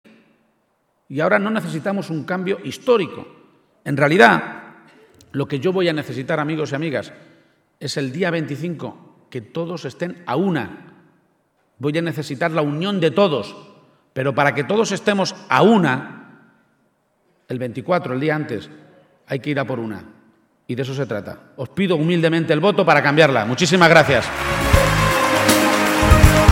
El candidato del PSOE a la Presidencia de Castilla-La Mancha, Emiliano García-Page, aseguraba hoy en Talavera de la Reina (Toledo) que “esta tierra no necesita un cambio histórico, ese ya lo hicimos nosotros, sino ir todos a una el día 24 a cambiarla”.